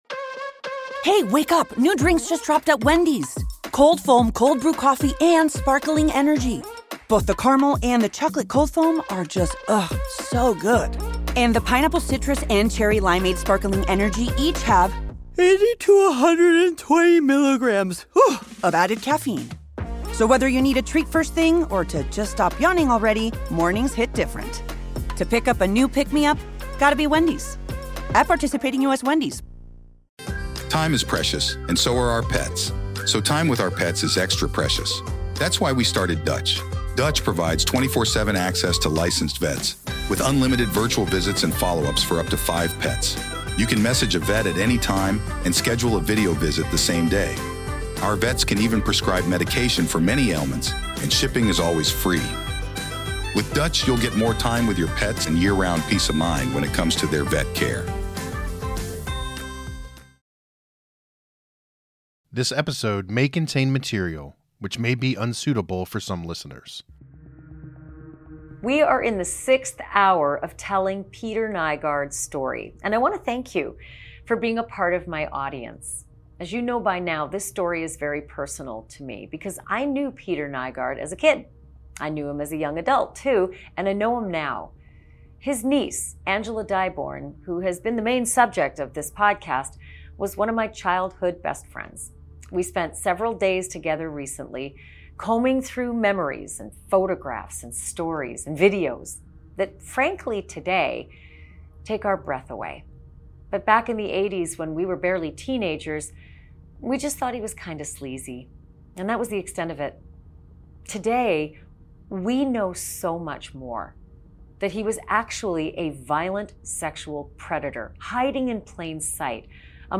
As Peter Nygard spends the final days of his life in a jail cell, Ashleigh Banfield talks with his victims about what justice looks like for them. She speaks to his family about the irony of Peter dying alone after a lifetime of being surrounded by people-- mostly women.